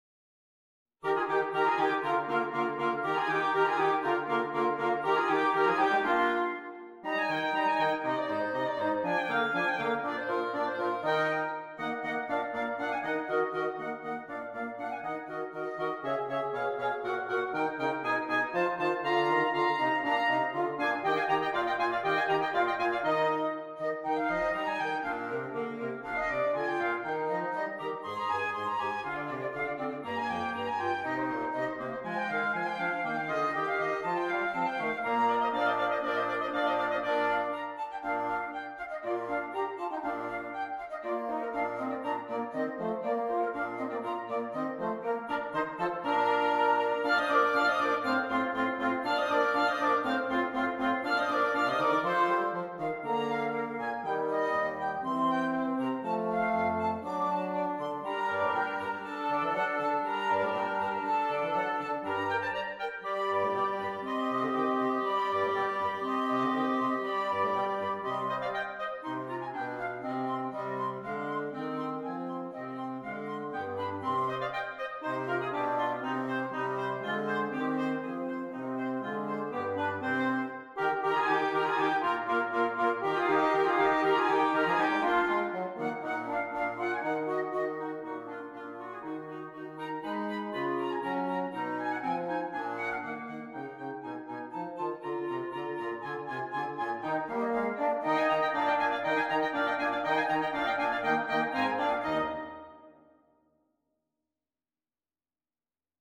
Woodwind Quintet